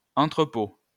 An entrepôt (English: /ˈɒntrəp/ ON-trə-poh; French: [ɑ̃tʁəpo]